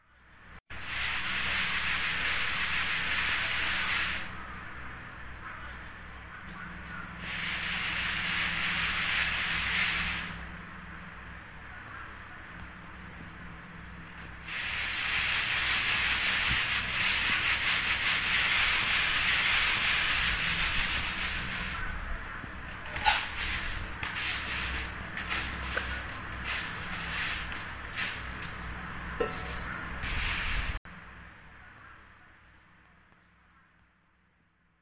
Tiếng Tràng gạo, Sàng gạo, Sẩy gạo … ngày xưa
Thể loại: Tiếng động
Tiếng Tràng gạo như tiếng những hạt gạo rơi lên nhau, Sàng gạo như âm thanh của việc lọc gạo qua sàng, còn Sẩy gạo như tiếng gạo bị xay nát...
tieng-trang-gao-sang-gao-say-gao-ngay-xua-www_tiengdong_com.mp3